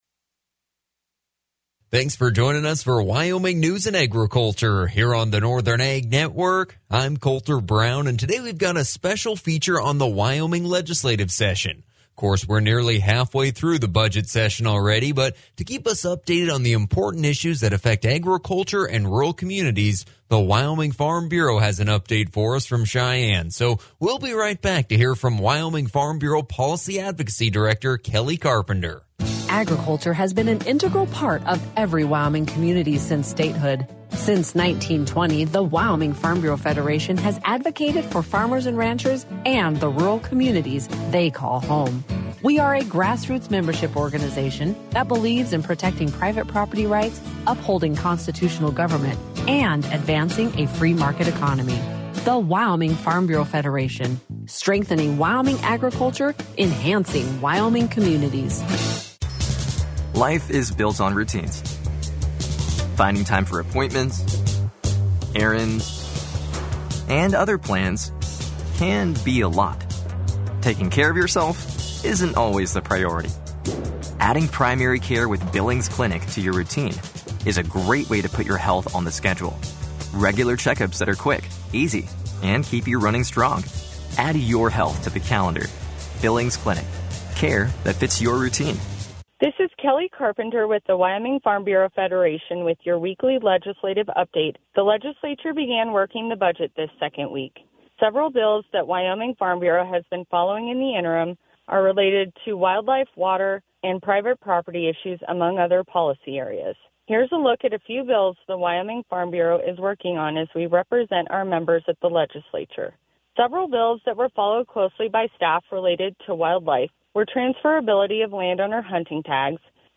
WyFB will be live on Northern Ag Network Radio every Thursday at 3:10!